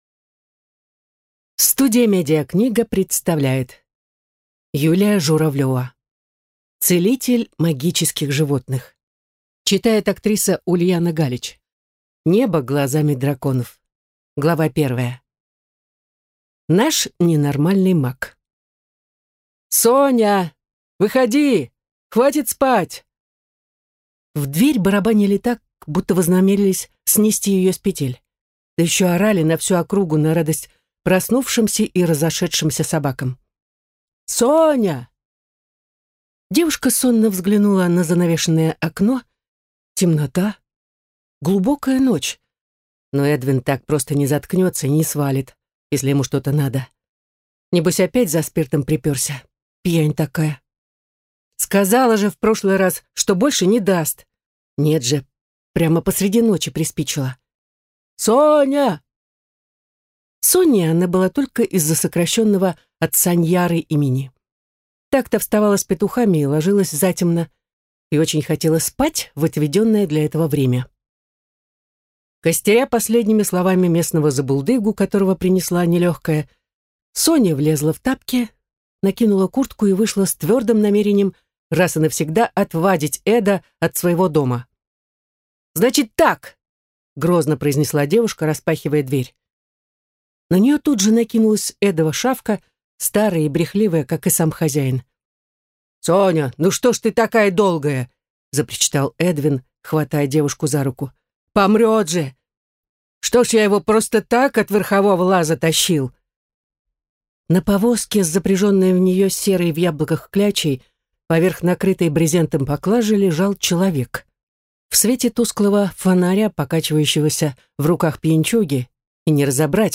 Аудиокнига Небо глазами драконов | Библиотека аудиокниг